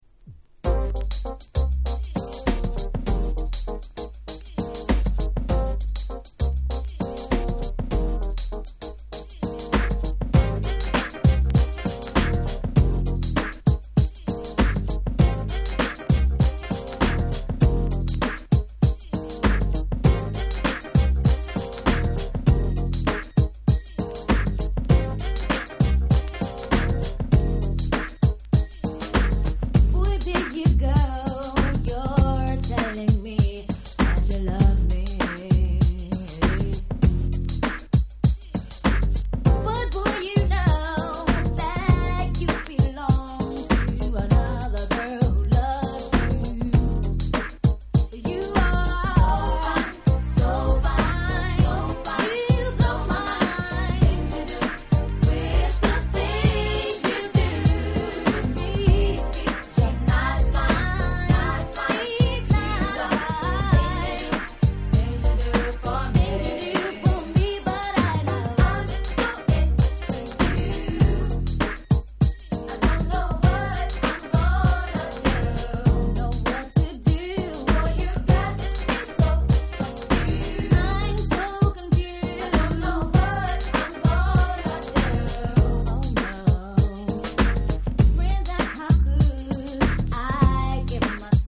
【Media】Vinyl LP
【Condition】B- (薄いスリキズ多め。プチノイズ箇所あり。試聴ファイルでご確認願います。)
92' Big Hit R&B LP !!